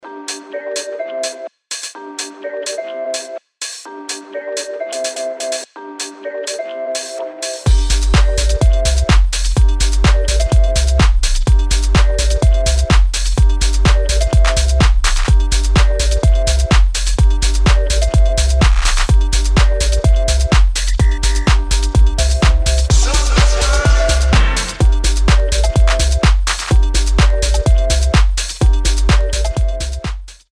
• Качество: 320, Stereo
мелодичные
Electronic
Tech House
электронные
ремиксы
Легкий звонок с какой-то знакомой мелодией